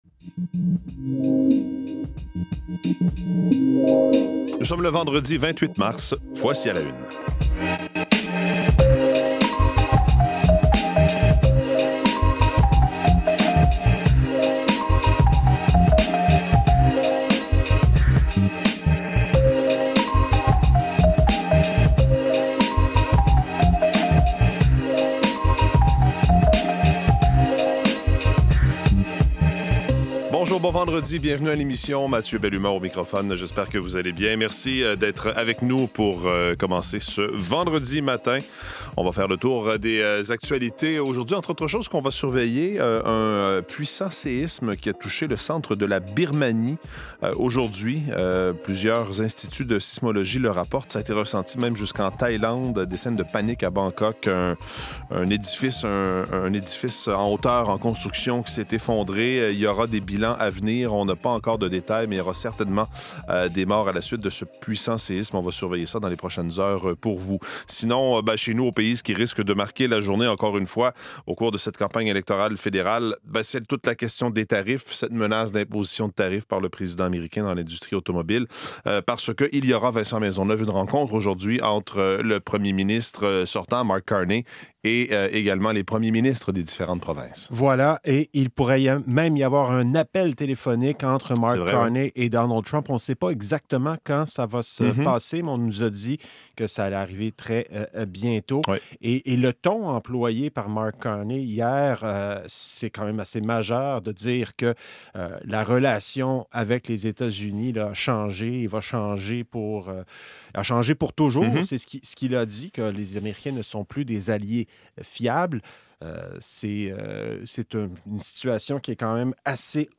Le tout sur un ton convivial. Un condensé de l'actualité pour bien démarrer la journée.